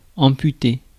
Ääntäminen
IPA: /ɑ̃.py.te/